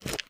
High Quality Footsteps / Concrete
MISC Concrete, Foot Scrape 11.wav